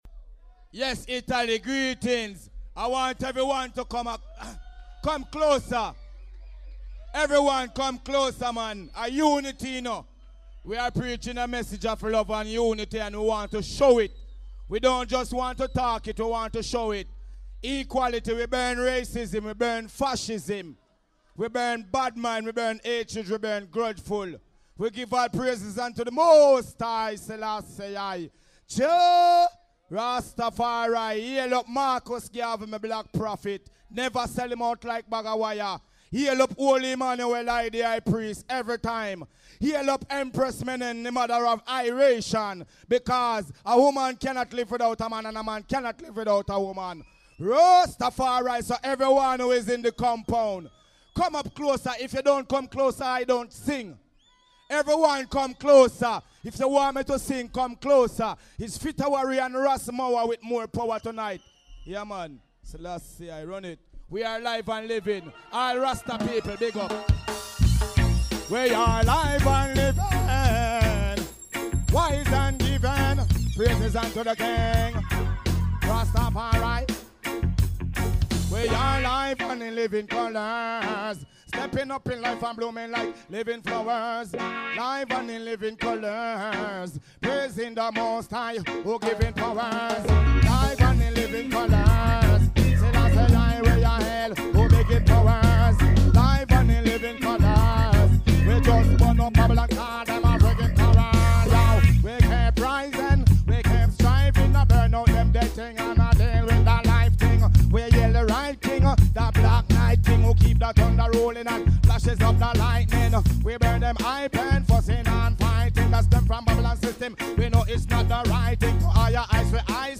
live at CSA Pacì Paciana - Bergamo - Italy